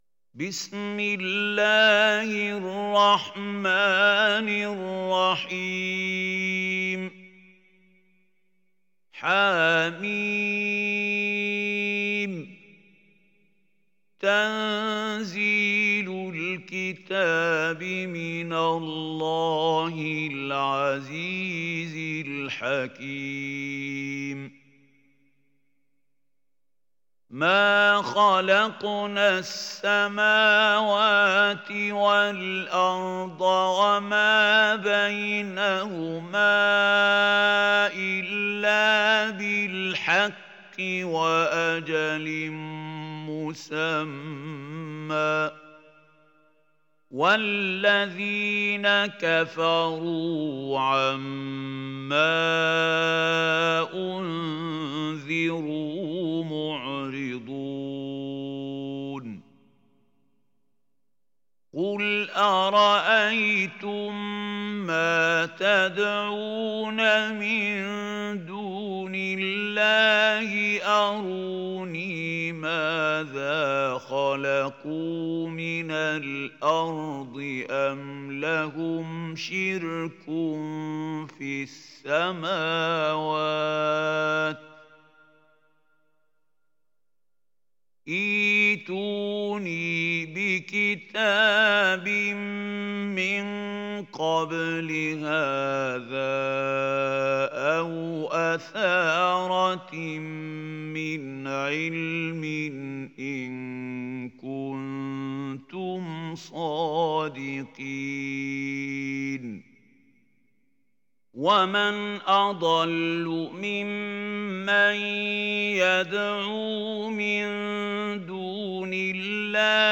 Sourate Al Ahqaf mp3 Télécharger Mahmoud Khalil Al Hussary (Riwayat Hafs)